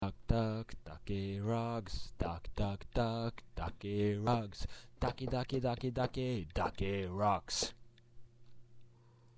the first two ducks were cut off in what I heard.